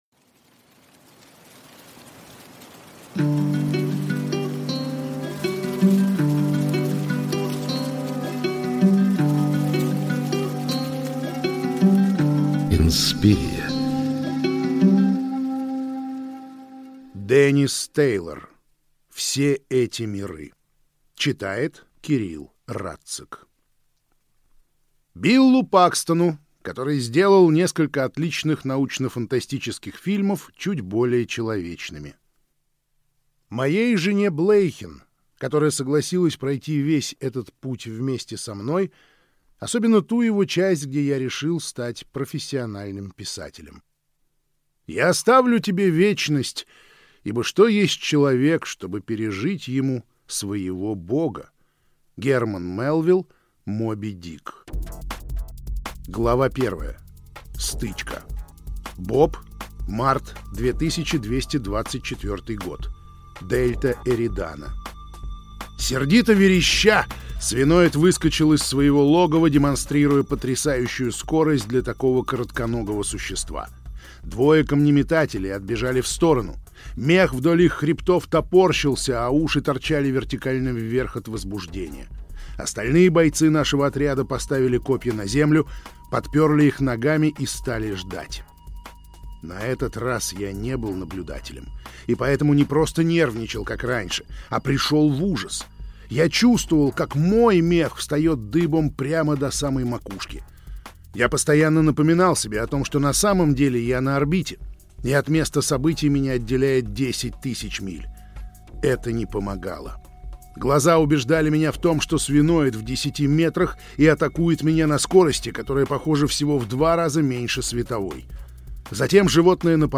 Аудиокнига Все эти миры | Библиотека аудиокниг